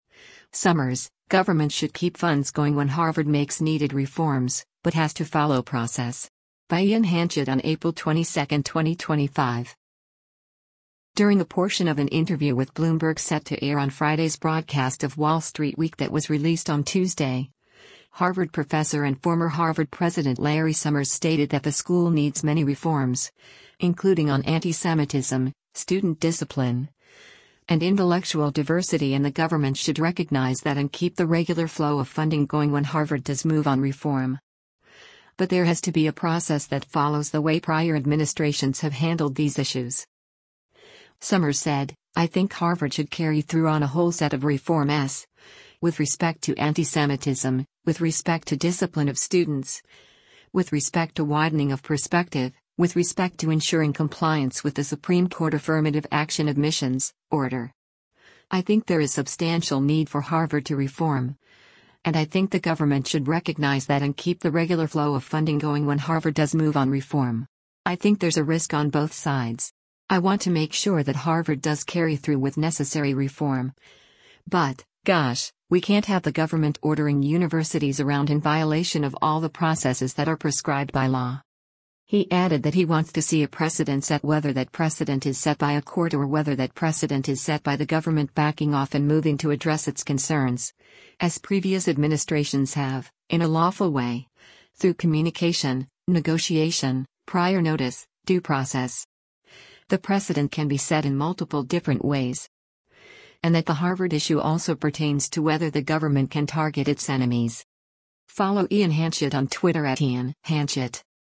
During a portion of an interview with Bloomberg set to air on Friday’s broadcast of “Wall Street Week” that was released on Tuesday, Harvard Professor and former Harvard President Larry Summers stated that the school needs many reforms, including on antisemitism, student discipline, and intellectual diversity and “the government should recognize that and keep the regular flow of funding going when Harvard does move on reform.”